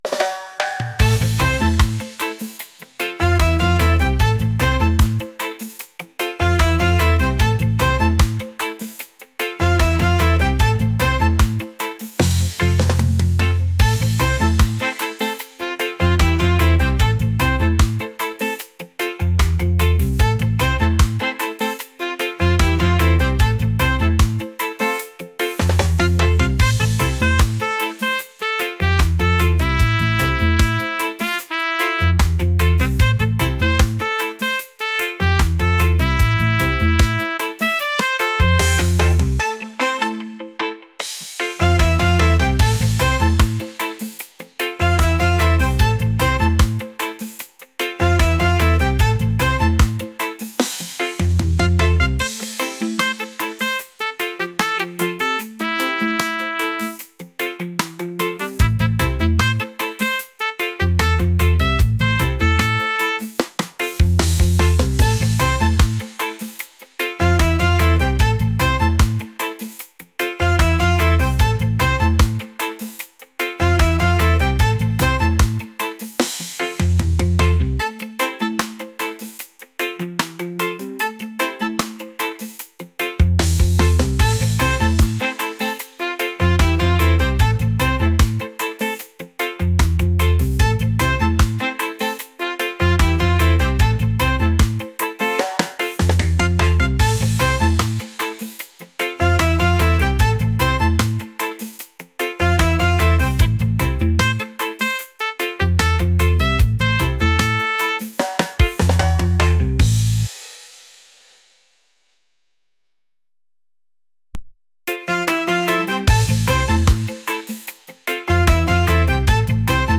positive | upbeat | reggae